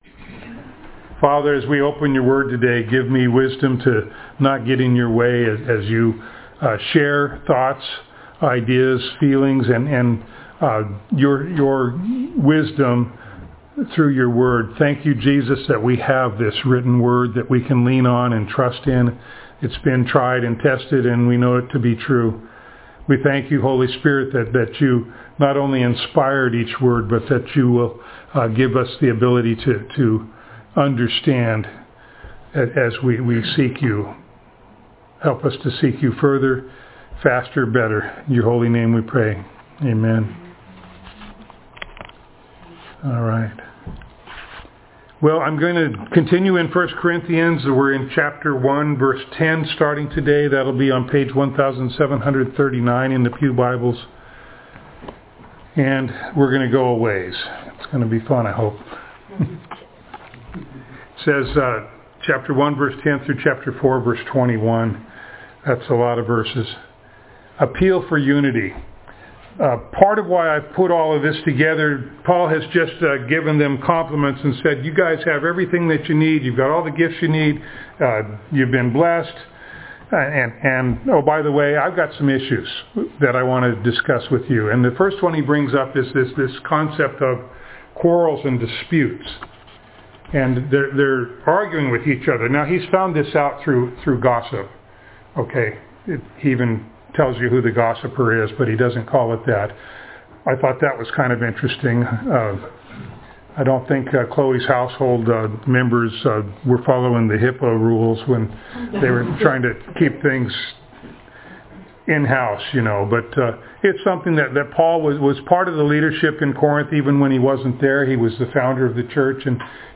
1 Corinthians Passage: 1 Corinthians 1:10-4:21 Service Type: Sunday Morning Download Files Notes « He Will Keep You